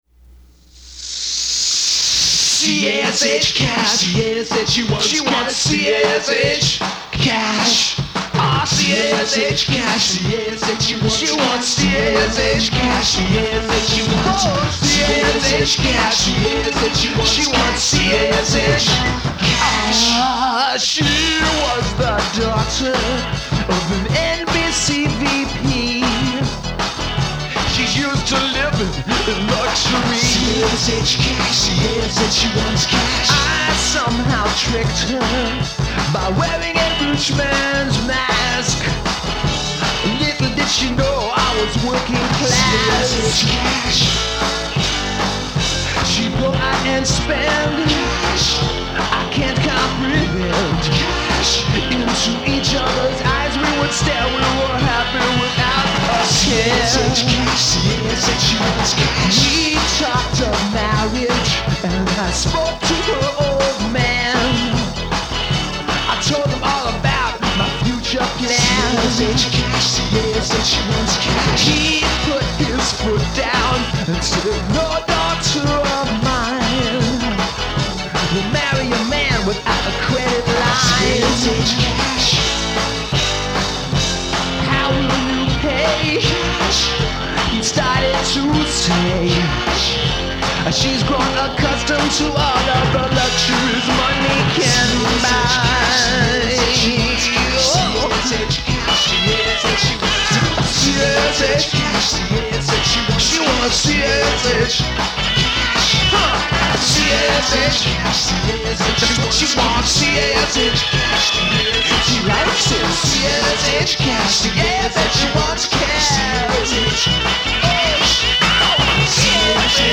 Guitar, Bass, percussion, Synthesizer & Vocals
Drums